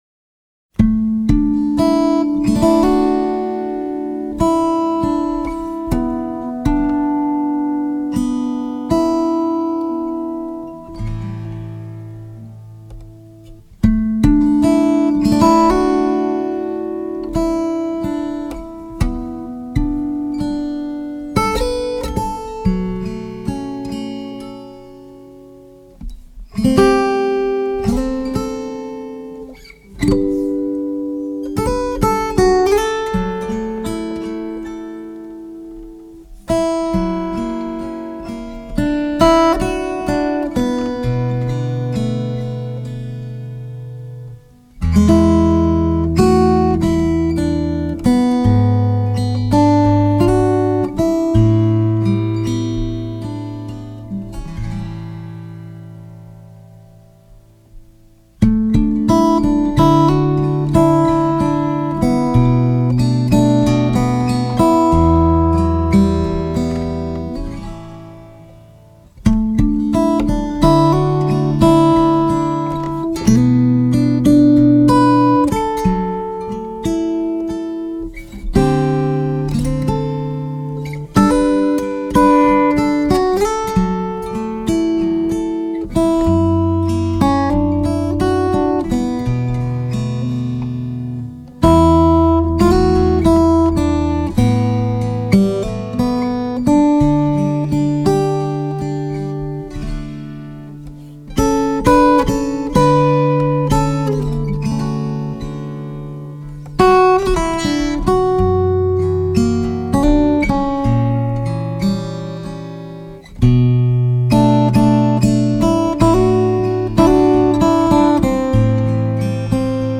★ 中文爵士革命！
★ 大師級精心母帶重製，鮮明優異音質更勝CD！
★ 發燒界備受喜愛的優質女聲，清純如天堂的嗓音！